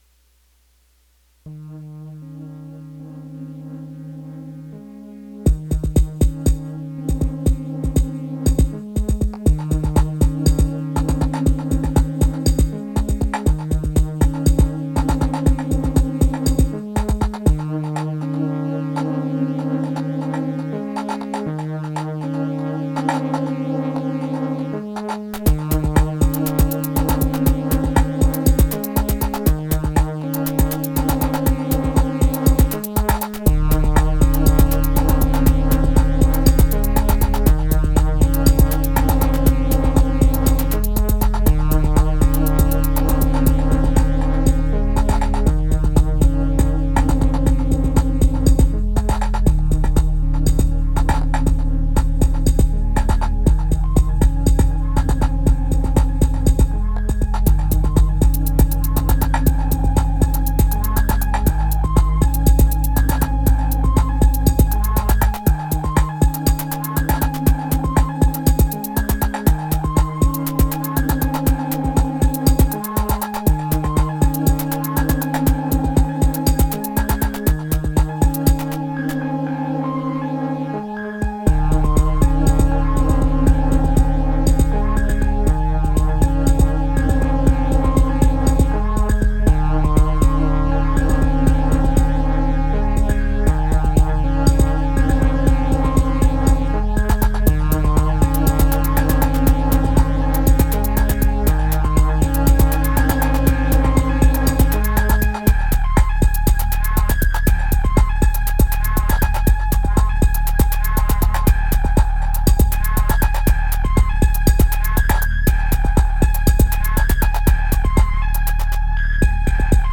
Elektron Gear Analog Rytm
Kind of messy jam.